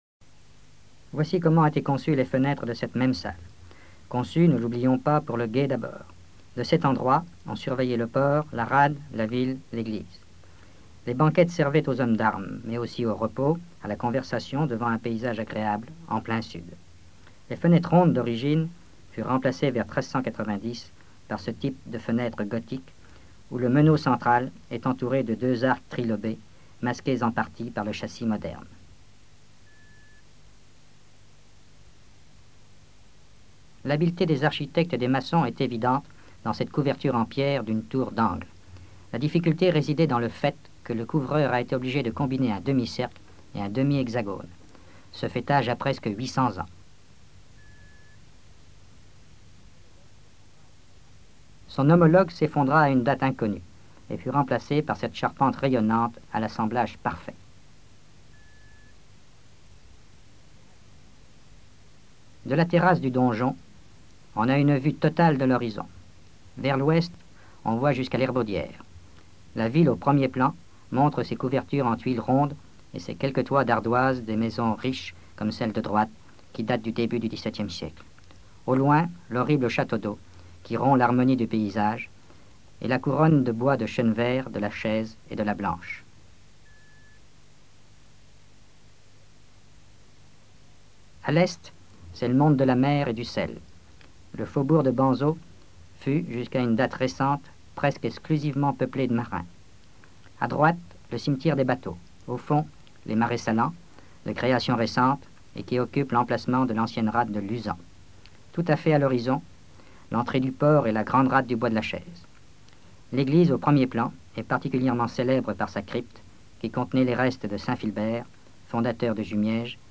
Présentation du musée du château de Noirmoutier
Catégorie Témoignage